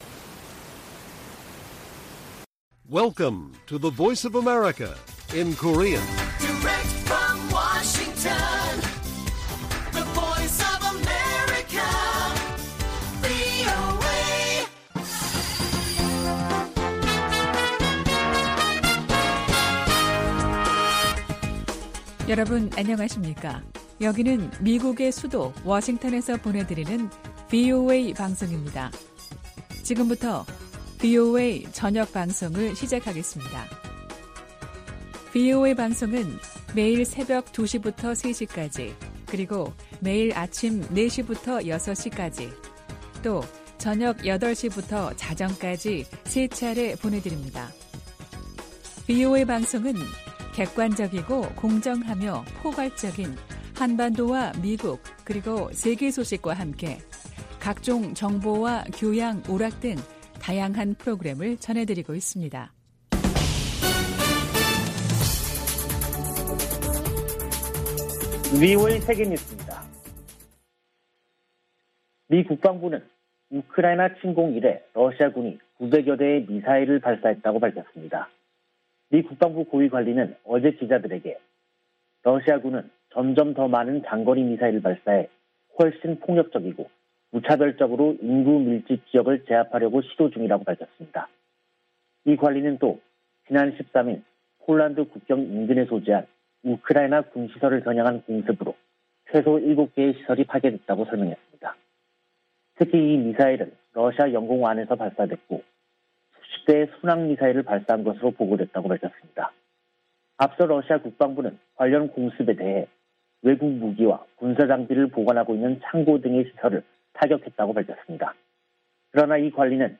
VOA 한국어 간판 뉴스 프로그램 '뉴스 투데이', 2022년 3월 15일 1부 방송입니다. 북한이 이동식발사대(TEL)에서 미사일을 쏠 때 사용하는 콘크리트 토대를 순안공항에 증설한 정황이 포착됐습니다. 백악관은 북한의 신형 ICBM 발사가 임박했다는 보도와 관련해, 예단하지 않겠다고 밝혔습니다. 백악관 국가안보보좌관이 중국 고위 당국자와 만났습니다.